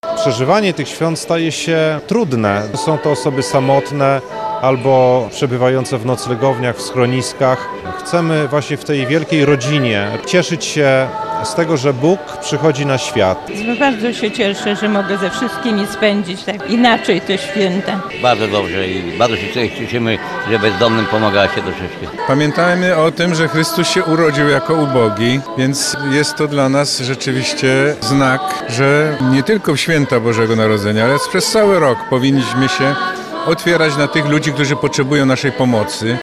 Blisko 300 osób wzięło udział w Wigilii Miłosierdzia zorganizowanej przez lubelską Caritas w hali Targów Lublin.
Ze sceny wybrzmiały kolędy śpiewane przez młodzież.
– O ubogich powinniśmy pamiętać nie tylko od święta – dodaje metropolita lubelski Stanisław Budzik.